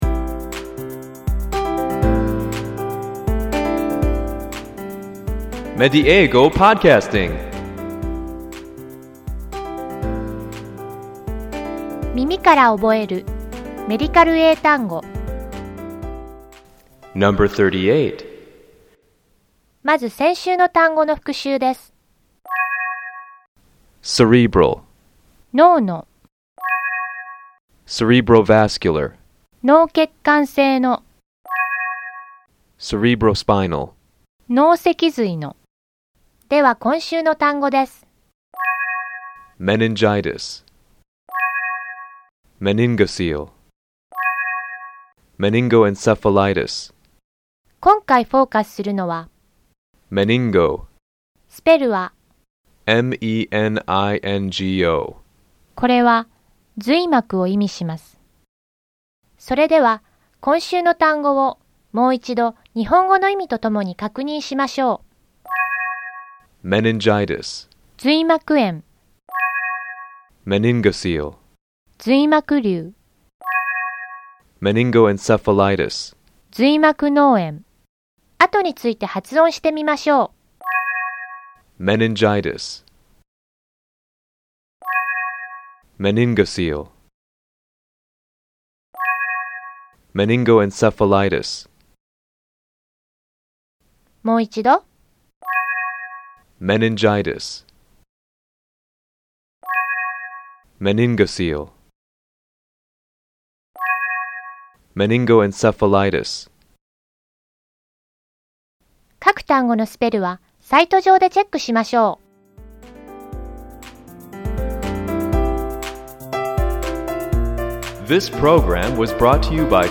この「耳から覚えるメディカル英単語」では，同じ語源を持つ単語を毎週3つずつ紹介していきます。ネイティブの発音を聞いて，何度も声に出して覚えましょう。